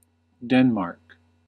Pronunciation: / ˈ d ɛ n m ɑːr k /
En-us-Denmark.ogg.mp3